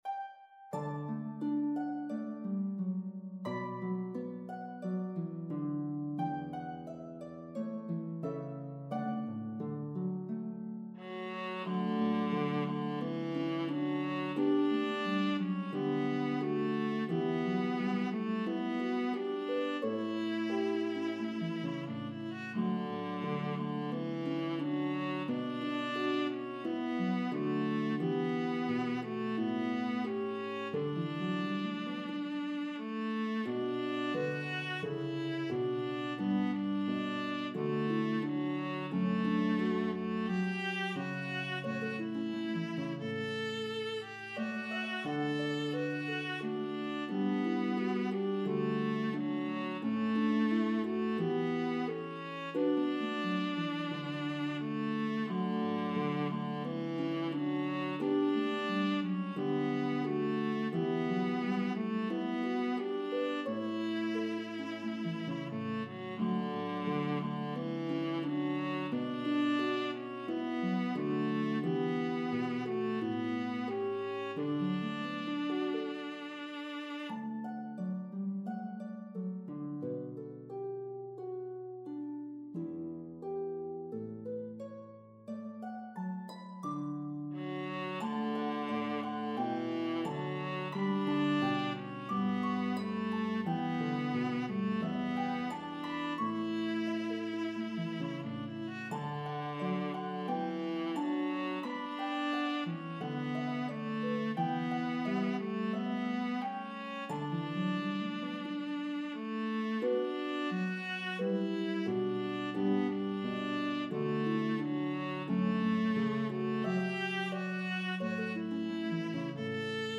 A common selection to perform at weddings.